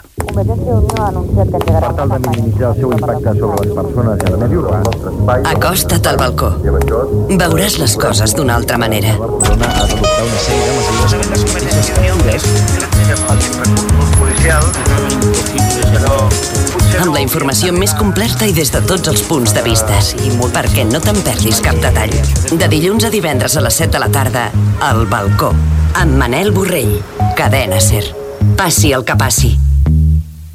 Promoció del programa
Info-entreteniment